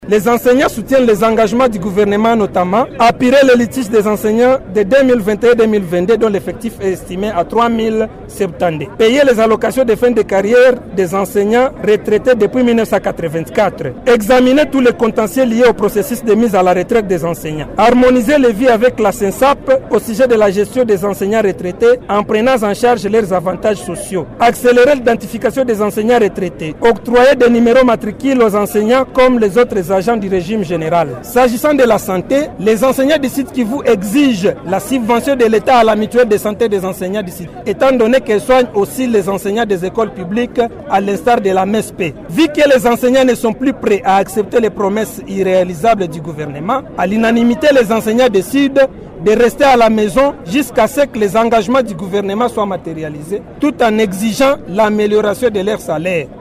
Cette assemblée générale s’est tenue à l’EP Matendo sur avenue Industrielle en commune d’Ibanda.